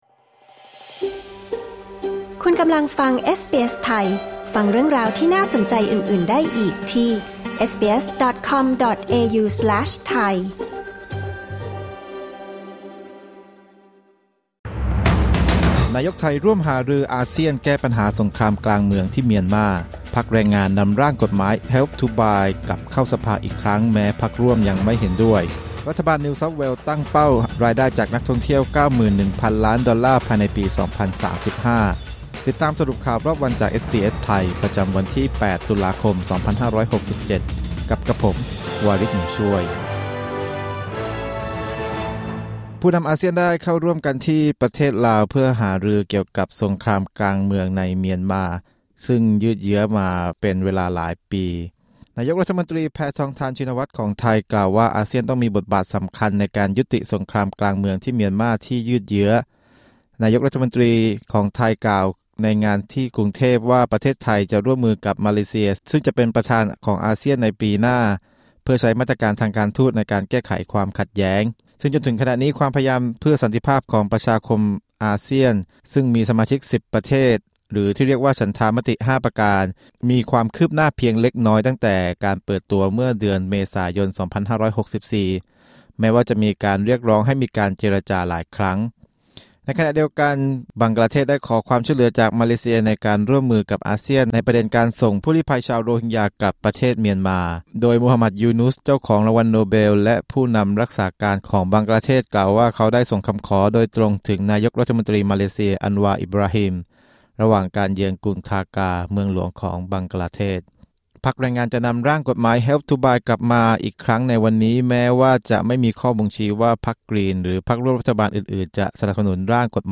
สรุปข่าวรอบวัน 8 ตุลาคม 2567